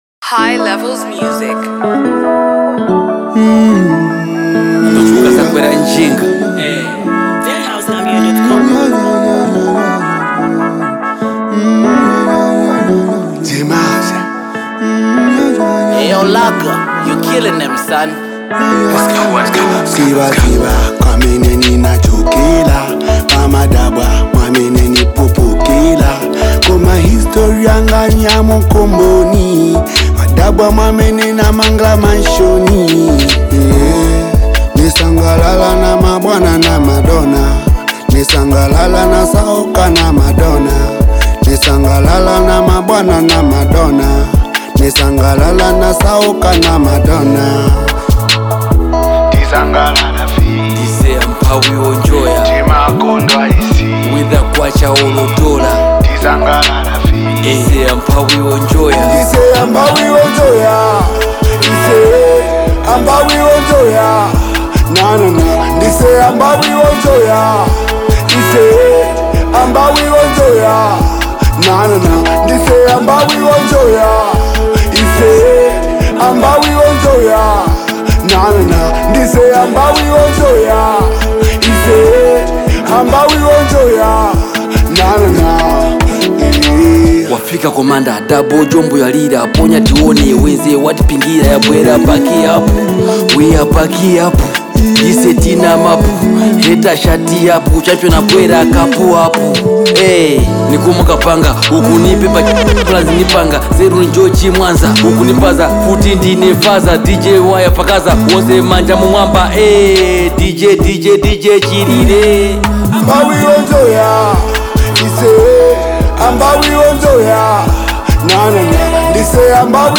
Don’t miss out on this uplifting anthem!